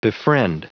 Prononciation du mot befriend en anglais (fichier audio)
Prononciation du mot : befriend